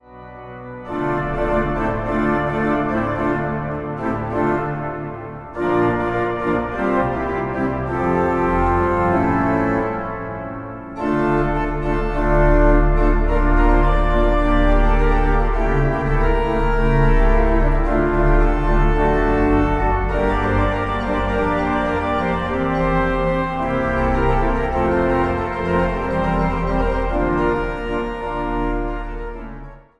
op het orgel van de Nieuwe Kerk van Middelburg.
13 stemmen
Instrumentaal | Orgel